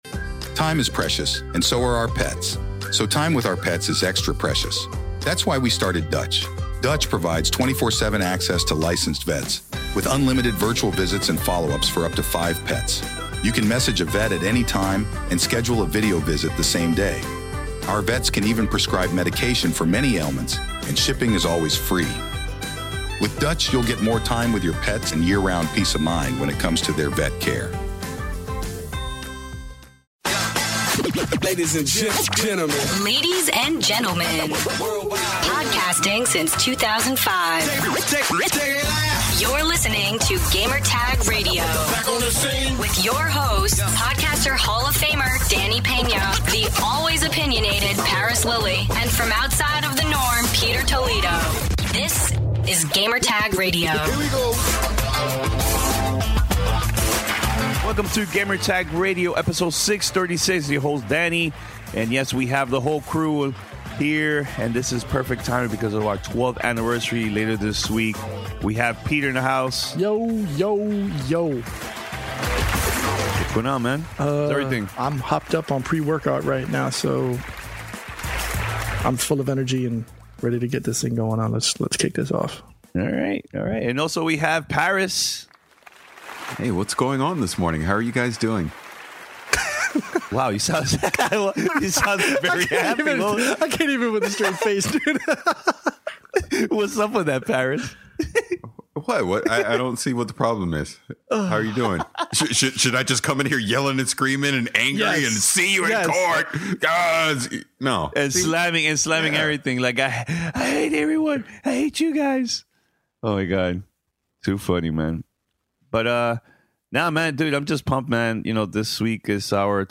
The crew answer questions from the listeners about the GTR crew recording together, E3 2017, Nintendo Switch, Mass Effect: Andromeda, and much more. Podcast Exclusive: Fifa 17 Ultimate Team Champions Regional Finals Miami coverage and interview with the finalists.